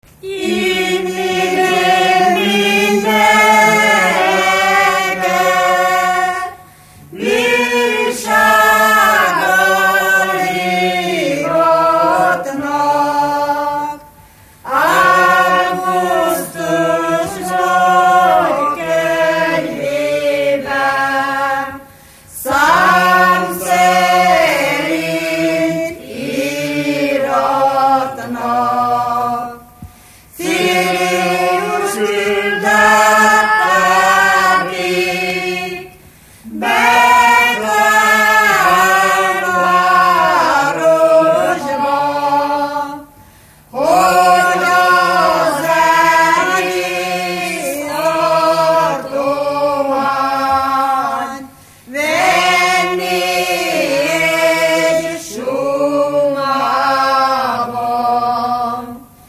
Moldva és Bukovina - Bukovina - Andrásfalva
Előadó: Férfiak, ének
Műfaj: Betlehemes
Stílus: 5. Rákóczi dallamkör és fríg környezete
Kadencia: 5 (b3) 2 1